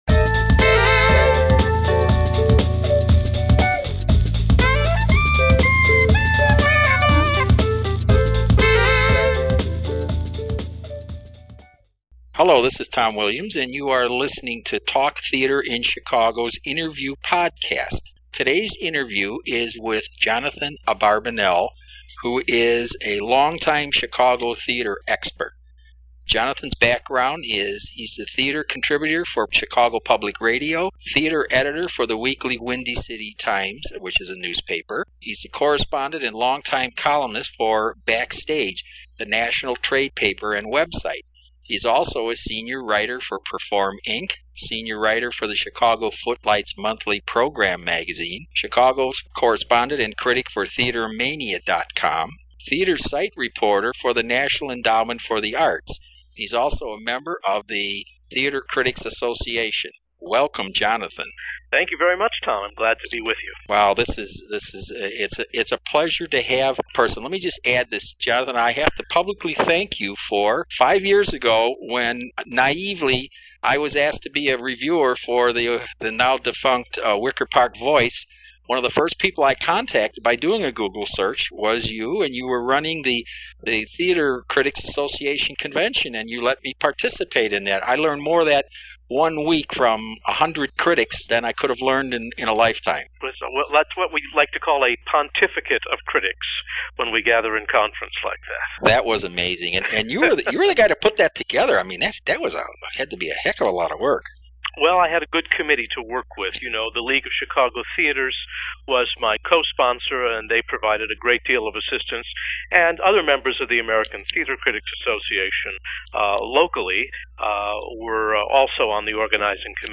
Take a listen to this great interview for the insight, advice, and frankness about what it takes to be an excellent Theatre Critic in Chicago.